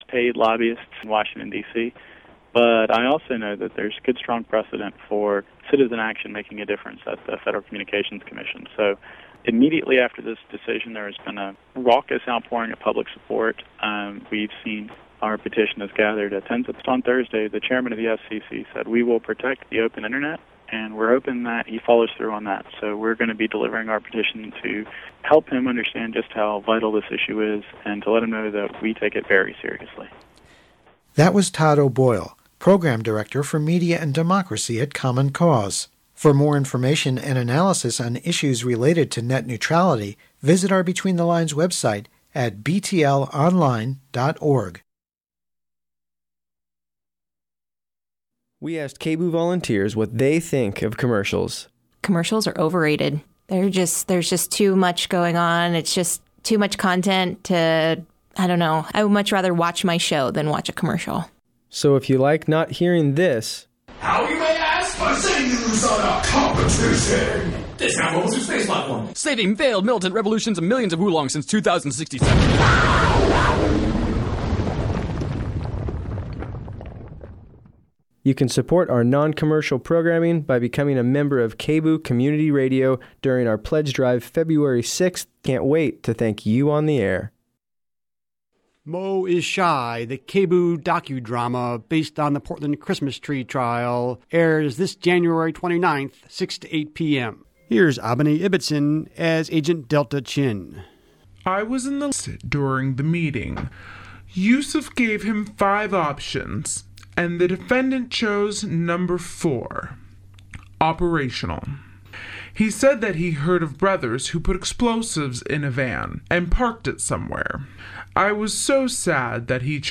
Different hosts each week bring a variety of guests and topics.
speaks with Howard Bloom, the Philosopher at the End of the Universe, about the political climate surrounding the 2014 Olymipics in Sochi, Russia.